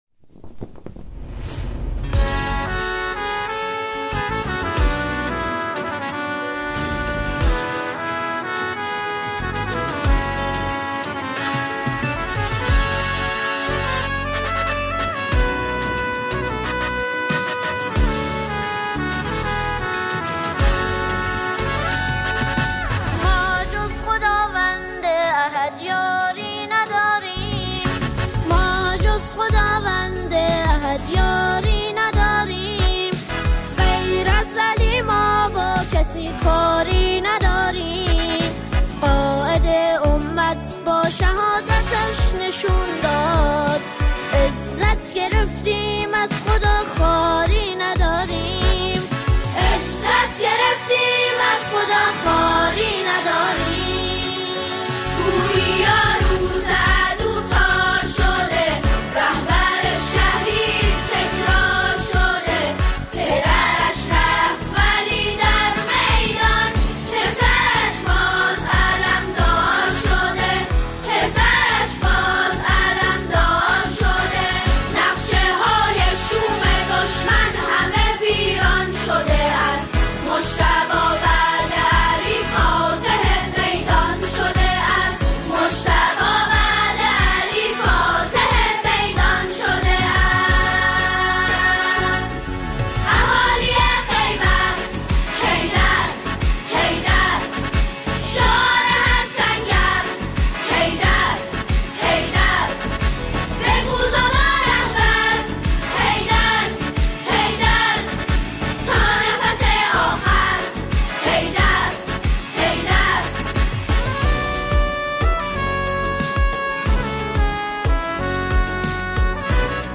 ژانر: سرود ، سرود انقلابی